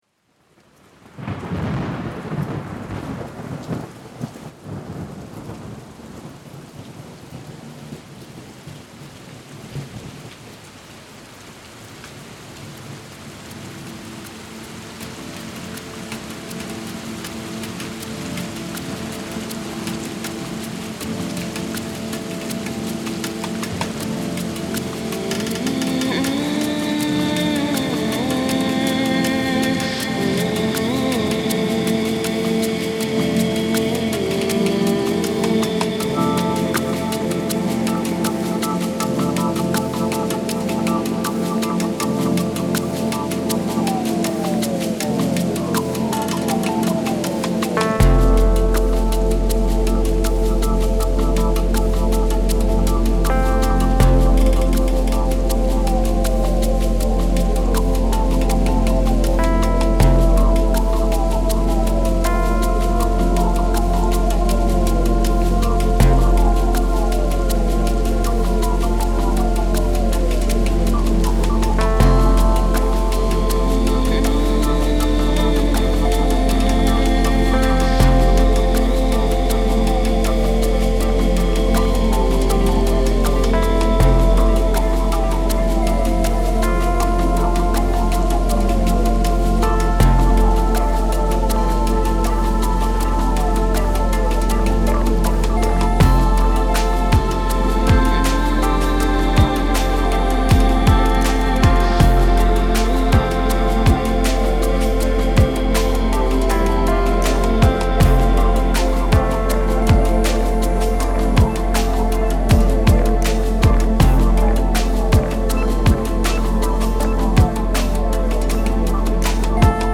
Удалось найти только хорошо пожатый мастер. Разжал , отмастерил и отстроил динамику и громкость.